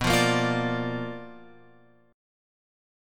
B Minor Major 7th Flat 5th